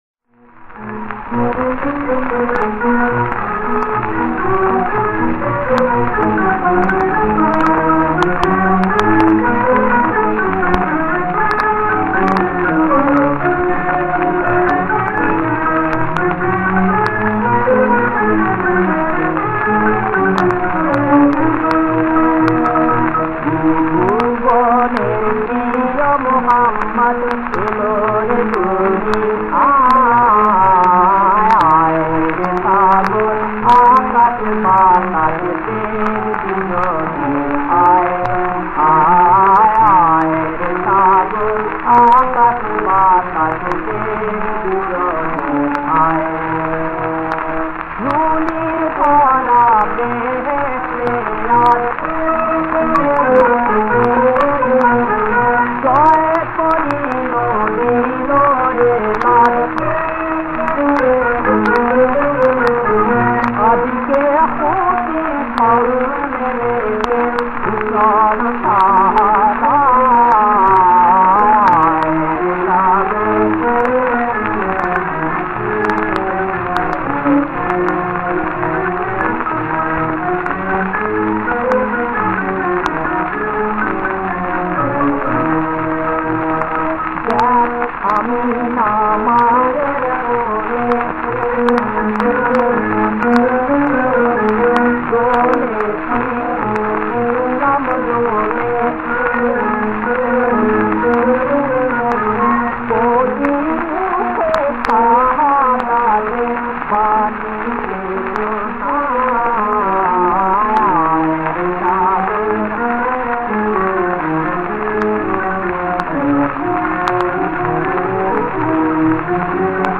• বিষয়াঙ্গ: ভক্তি [ইসলামি গান]